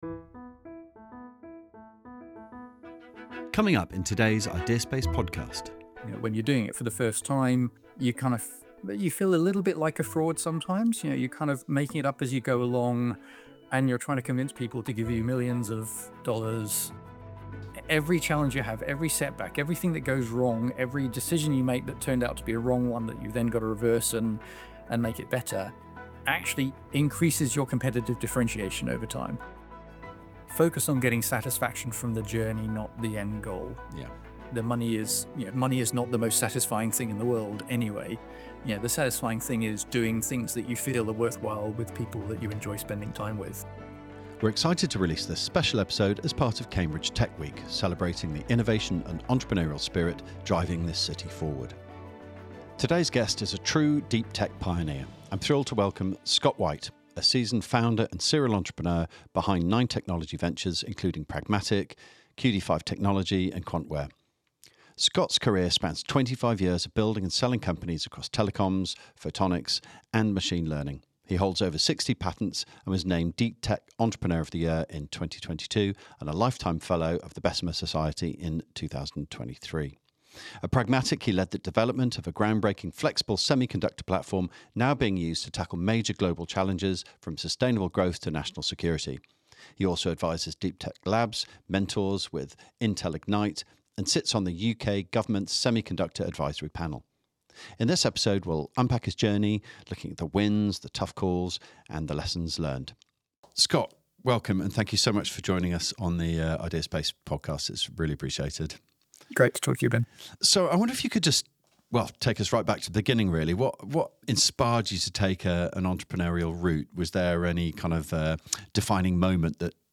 In this conversation, we dive into: